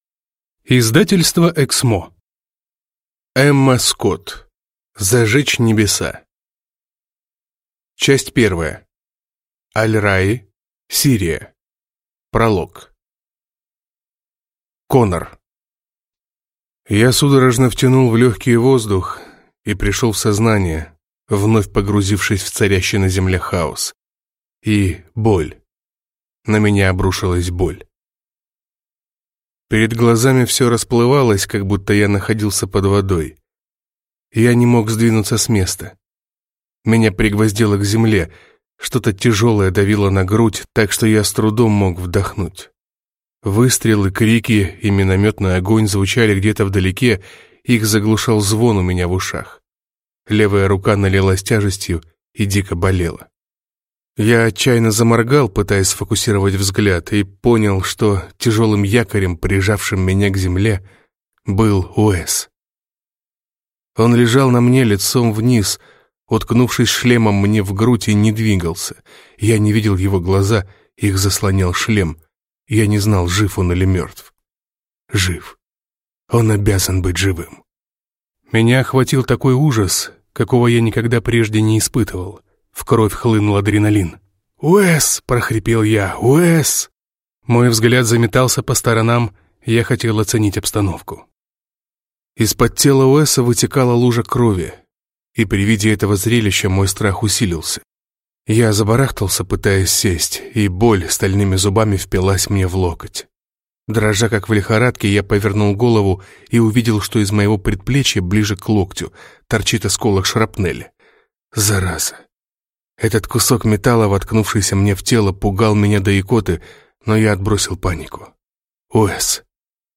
Аудиокнига Зажечь небеса | Библиотека аудиокниг